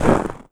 High Quality Footsteps
STEPS Snow, Run 07.wav